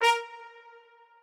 strings1_29.ogg